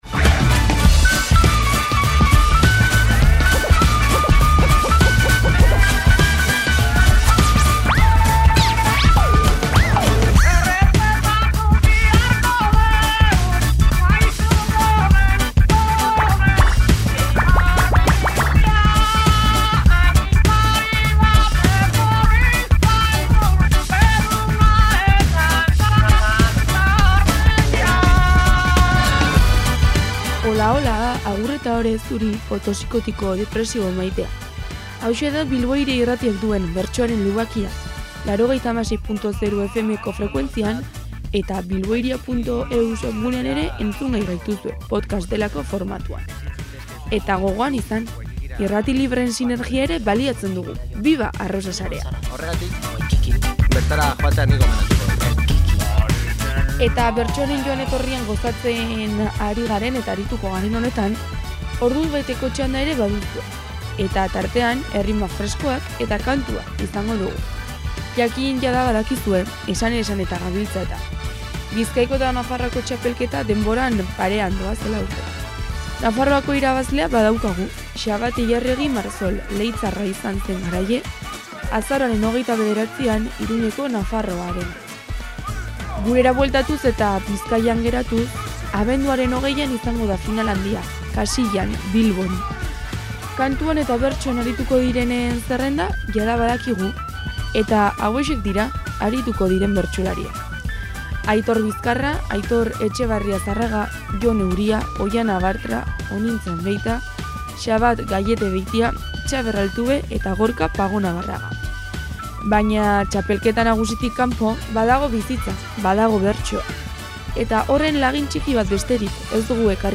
Bertsotan hasi nahi duen señorentzat topaleku izan nahi du, giro feministan eta umoretsuan noski. Udazkeneko saioa ekarri dugu guk, azaroaren 19an, asteazkenarekin BIRA kulturgunean eginiko saioa; badakizue, aukeraketa diktatorialean.